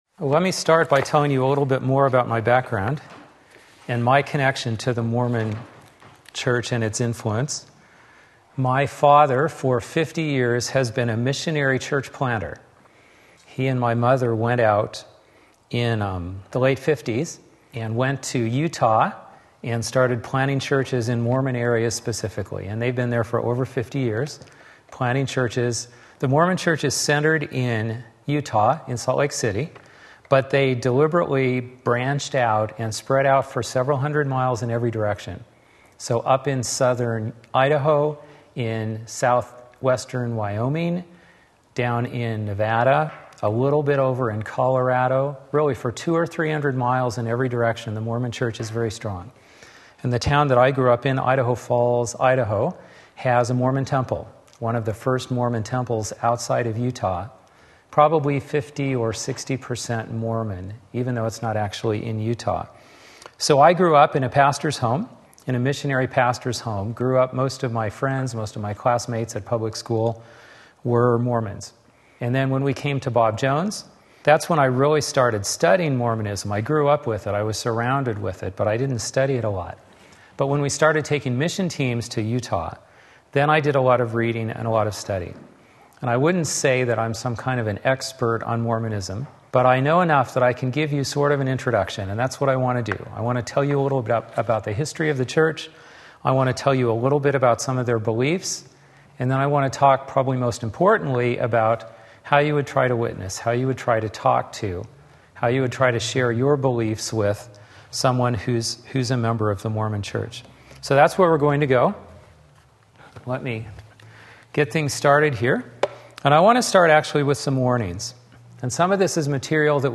Sermon Link
The Gospel and Mormonism various texts Wednesday Evening Service